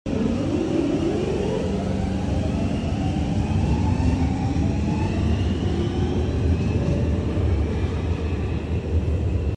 10 seconds of Helsinki Metro sound effects free download